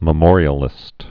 (mə-môrē-ə-lĭst)